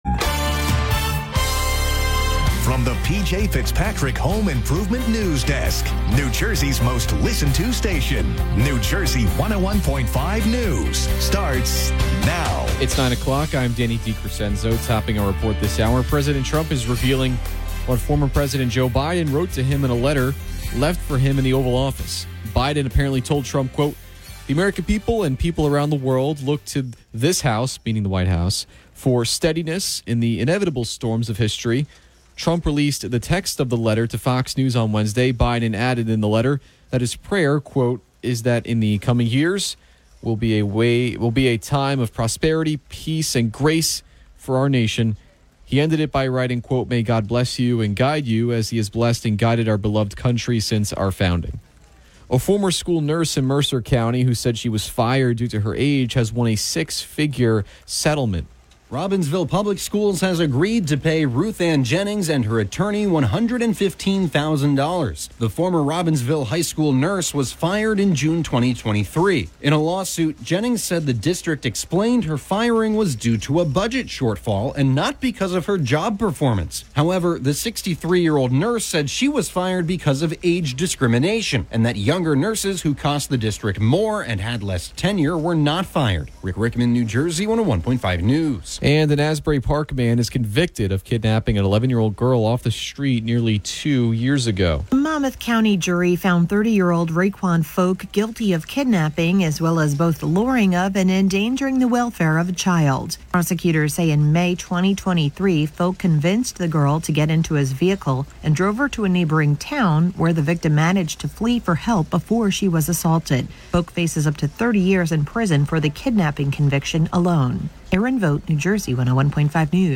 The latest New Jersey news and weather from New Jersey 101.5 FM, updated every hour, Monday through Friday.